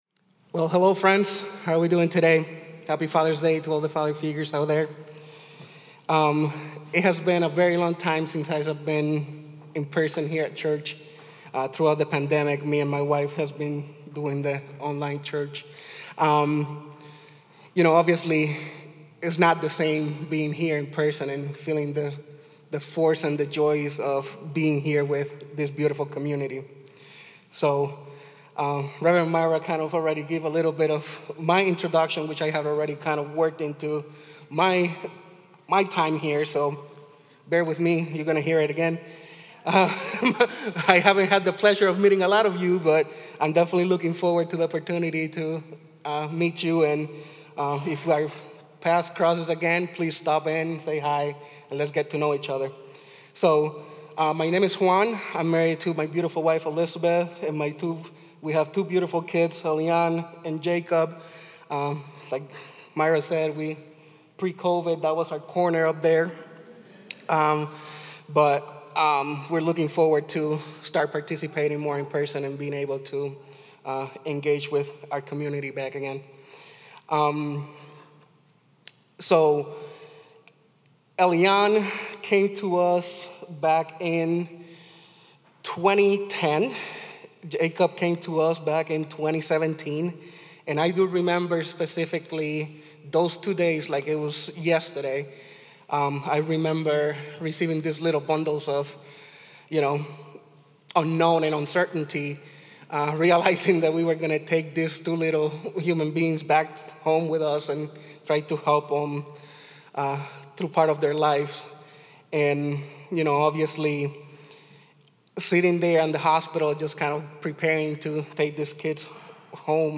Father’s Day Liturgy 2021